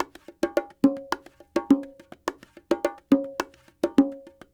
44 Bongo 13.wav